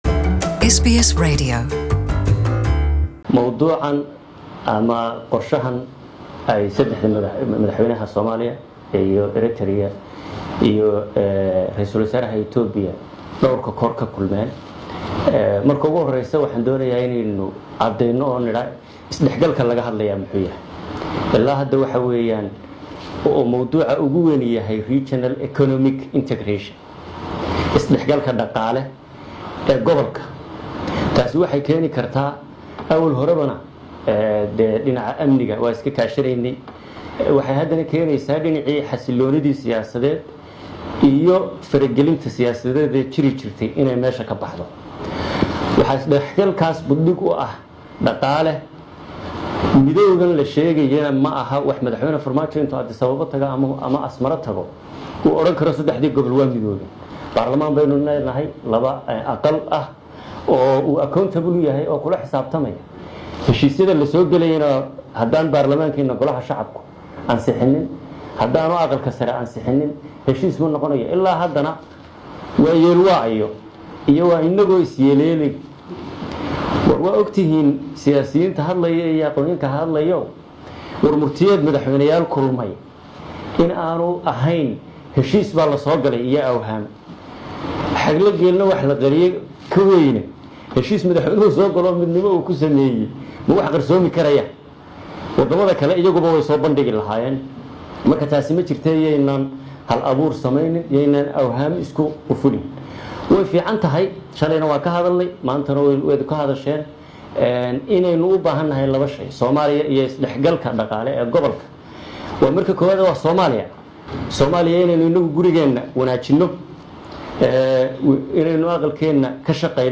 Somali deputy PM speech at Djibouti conference
Qudbo uu RW ku xigeenka Soomaliya ka jeediyay shir ka socda Djibouti.